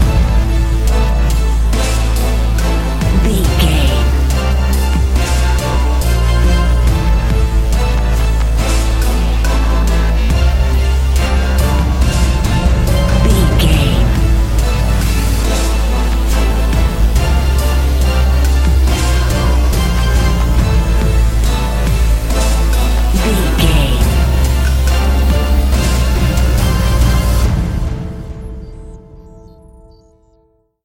Aeolian/Minor
Slow
strings
brass
drum machine
percussion
orchestral hybrid
dubstep
aggressive
energetic
intense
synth effects
wobbles
driving drum beat
epic